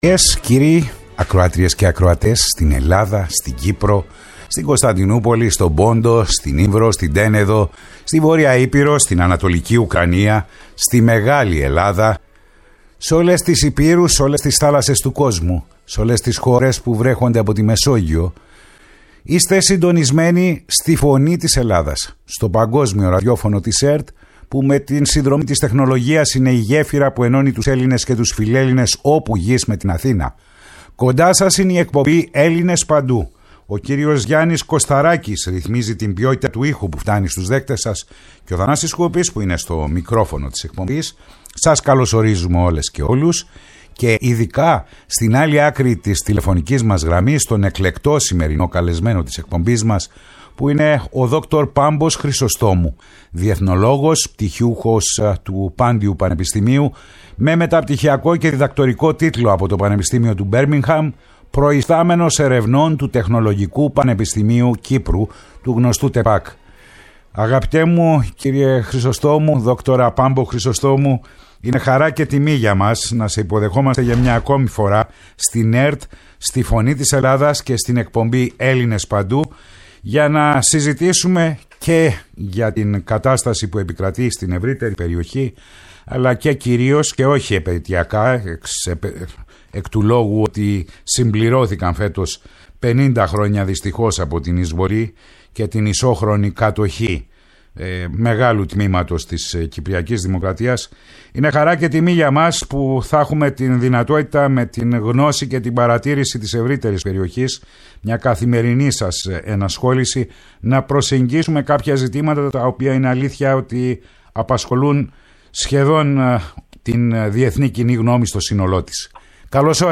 Η ΦΩΝΗ ΤΗΣ ΕΛΛΑΔΑΣ Ελληνες Παντου ΣΥΝΕΝΤΕΥΞΕΙΣ Συνεντεύξεις